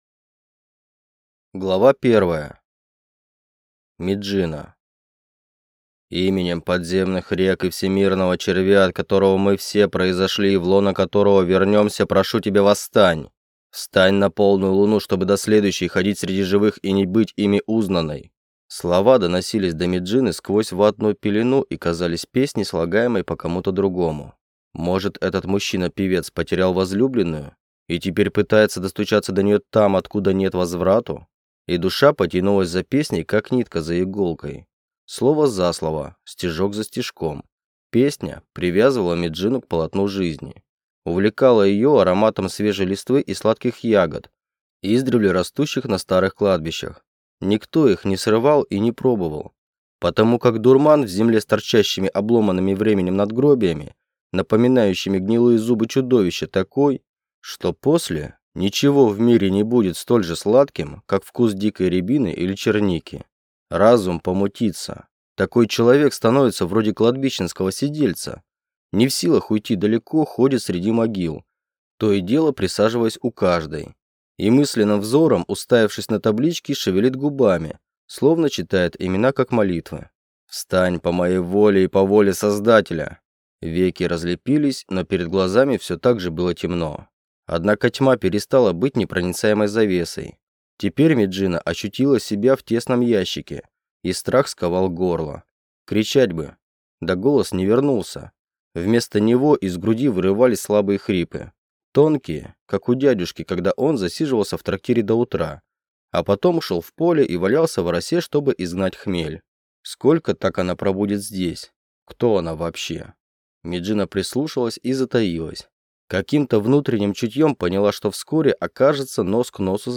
Аудиокнига Нежить и некромант | Библиотека аудиокниг
Прослушать и бесплатно скачать фрагмент аудиокниги